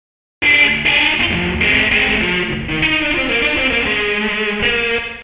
（キー F /テンポ 100 に変更）
5. Comp → Chorus → AmpSim → Reverb
リバーブは、プリセットのままなので非現実的なくらい濃い目ですね。